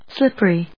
音節slip・pery 発音記号・読み方
/slíp(ə)ri(米国英語), ˈslɪpɜ:i:(英国英語)/